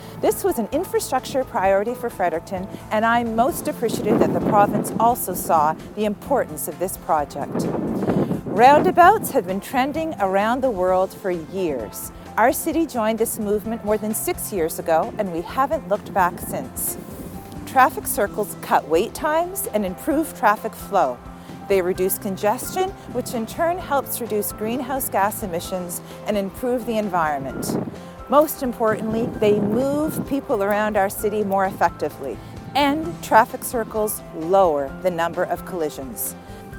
La mairesse de Fredericton, Kate Rogers, se dit ravie que le projet voit enfin le jour :
Kate-Rogers-extrait.mp3